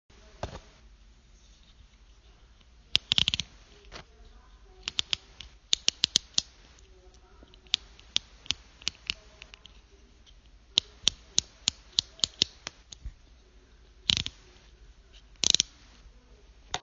lock blade knife » lock blade knife open 04
描述：Pocket knife, a sound of blade being unfolded.
标签： knife click oneshot
声道立体声